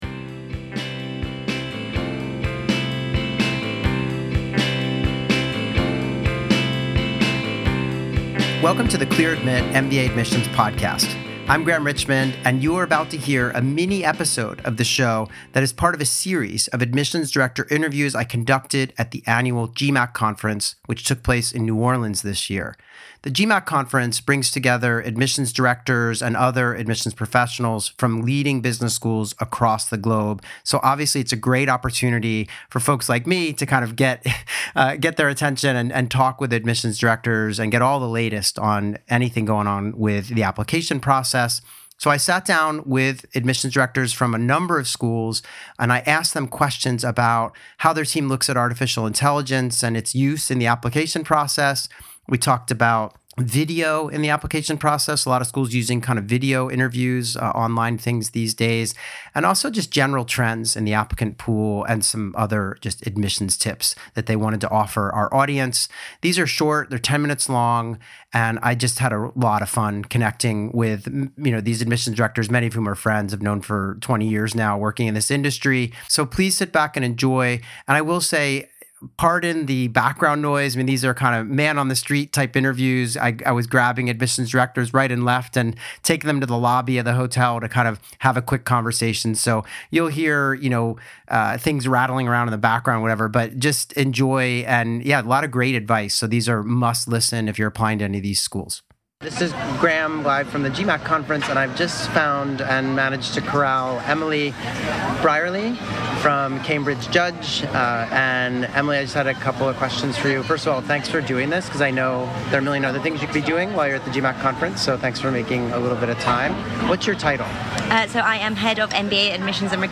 Live Admissions Q&A